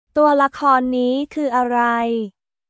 トゥアラコンニー クー アライ？